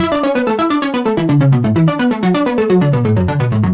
Index of /musicradar/french-house-chillout-samples/128bpm/Instruments
FHC_Arp A_128-A.wav